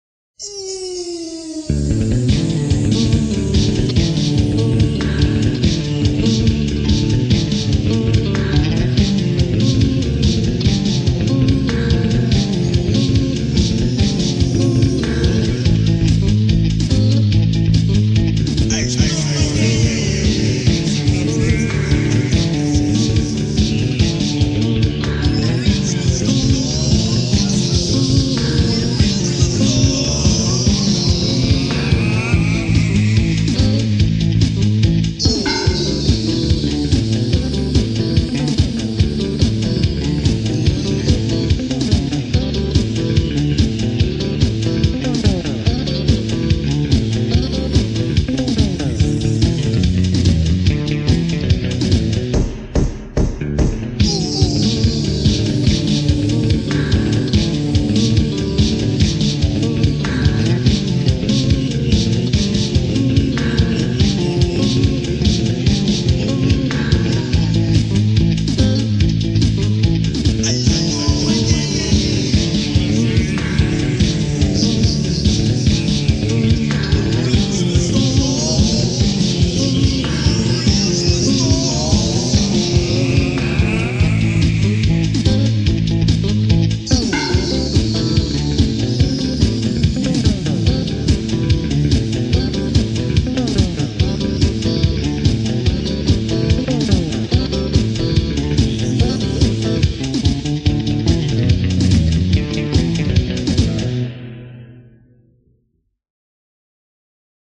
bass guitar and vocals
percussions